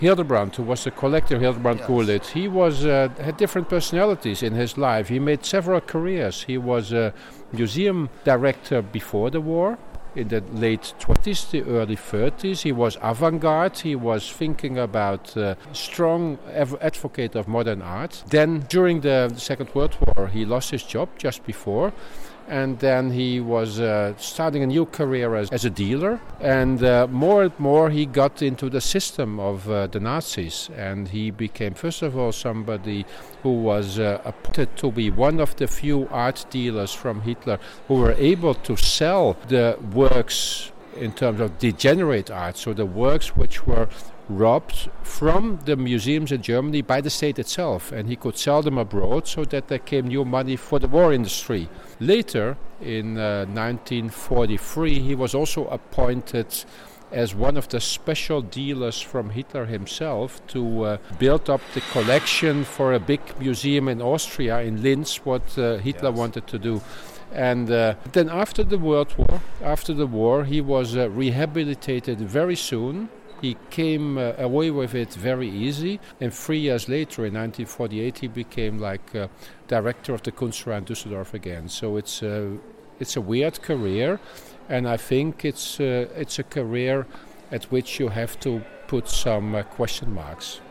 Un interviu în exclusivitate